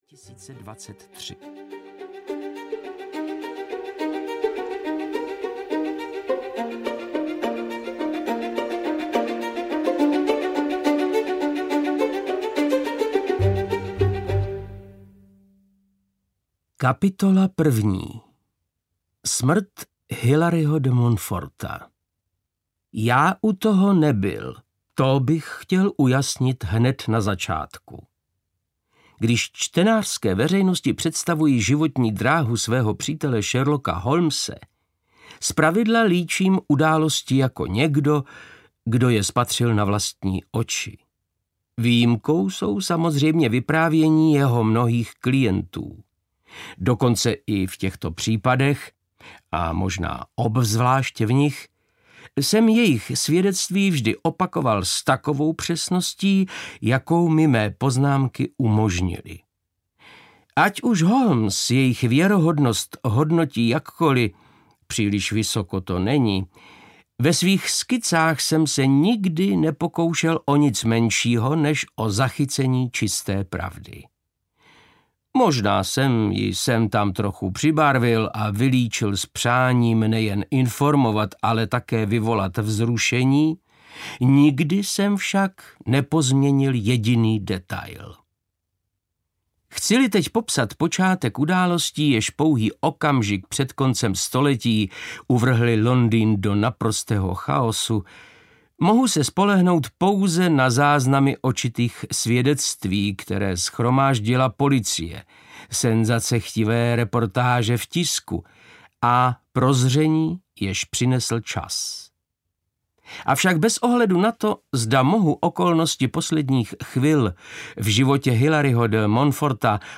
Sherlock Holmes a Boží dech audiokniha
Ukázka z knihy
• InterpretVáclav Knop